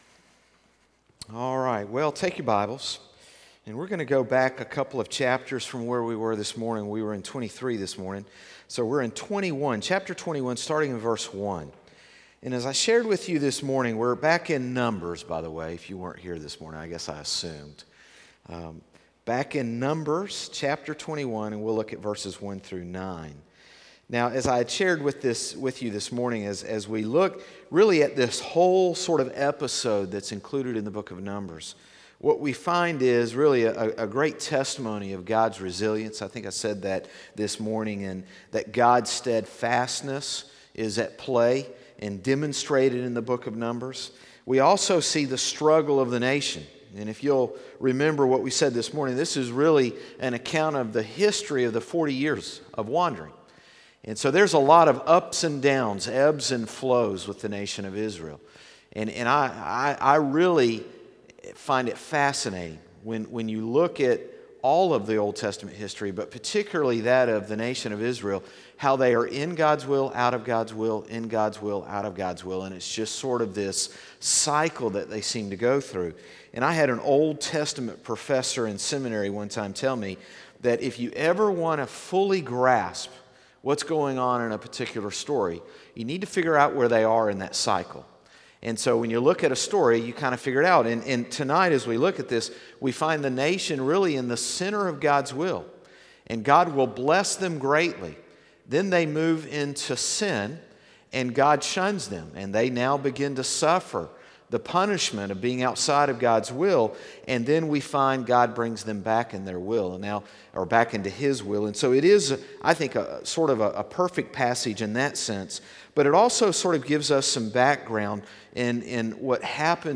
Sermons - Concord Baptist Church
Evening Service 3-25-18.mp3